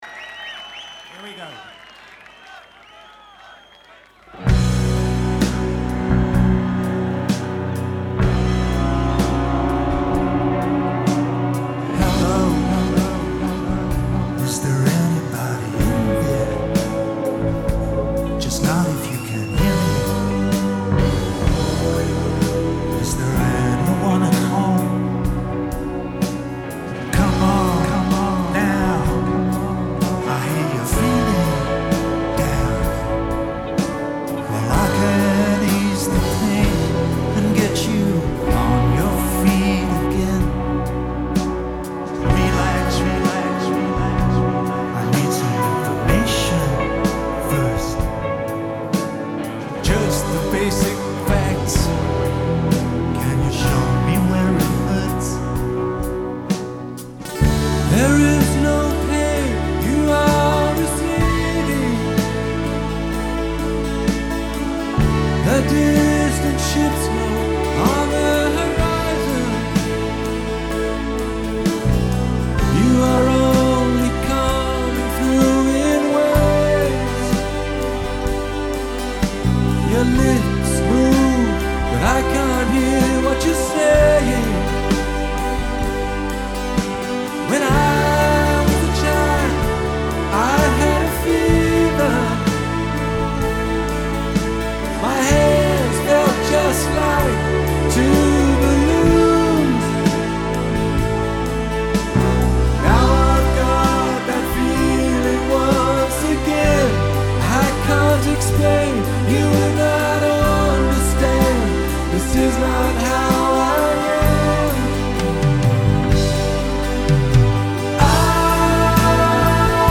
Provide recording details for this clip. Recorded on July 2, 2005, in London